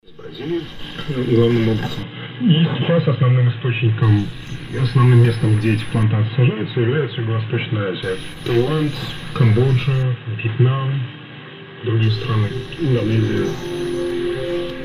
В заключение предлагаю файлы с записями работы этого ФНЧ.
Фильтр работает 2_4,  6_8, 10_12 секунды.